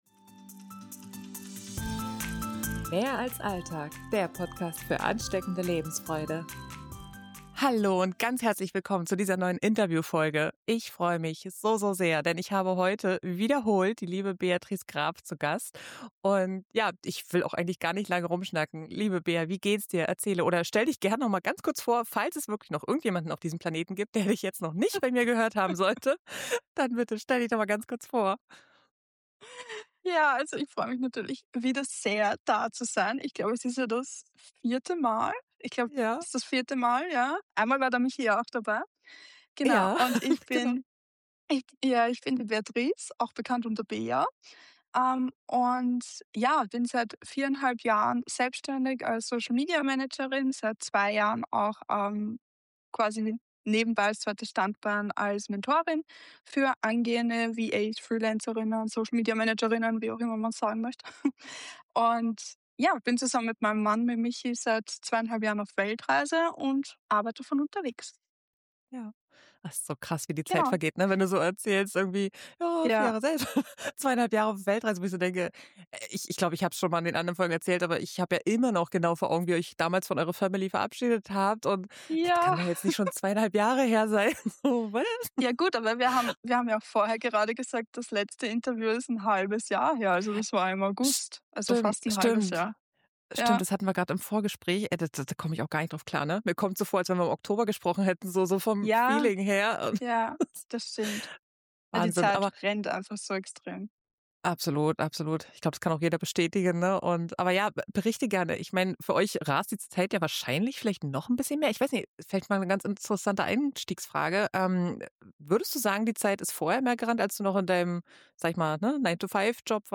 Ein ehrliches, inspirierendes Gespräch über Selbstständigkeit, Beziehungen auf Reisen und die Frage: Was hindert dich eigentlich daran, deinen Traum zu leben?